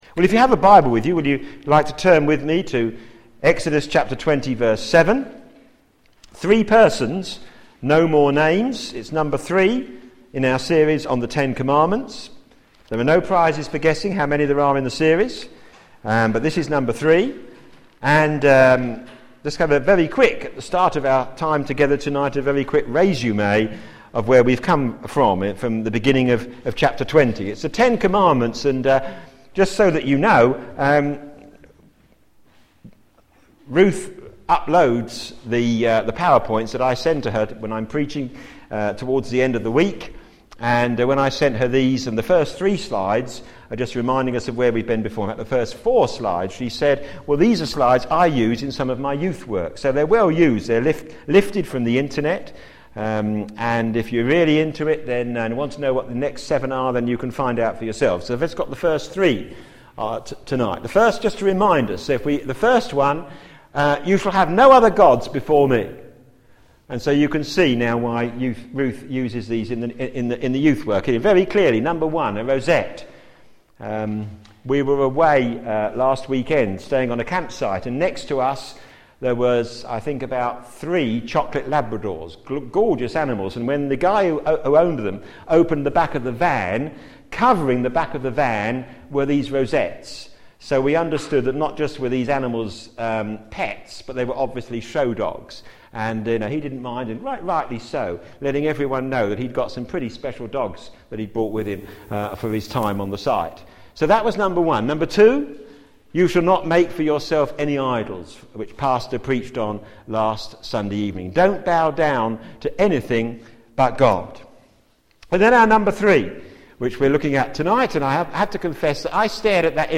p.m. Service
Sermon